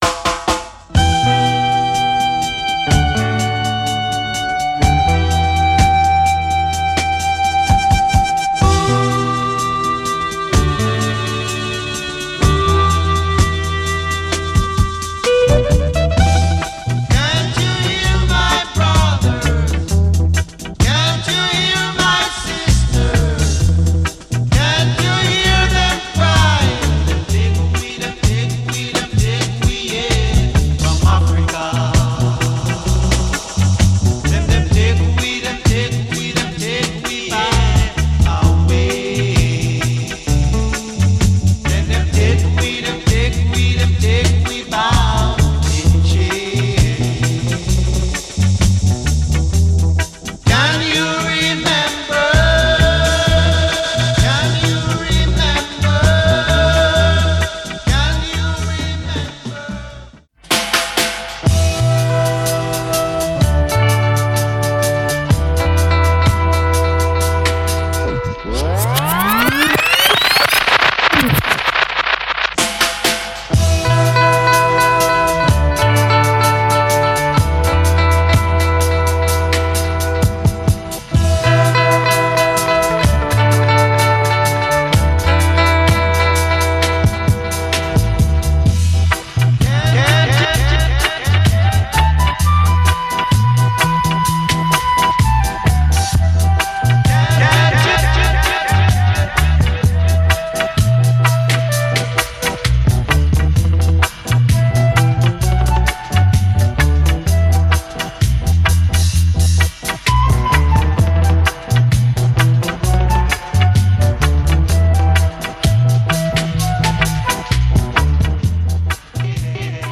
Late 70s and early 80s productions
rocksteady
rolling his musical wheel into the echo chamber.